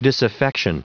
Prononciation du mot disaffection en anglais (fichier audio)
disaffection.wav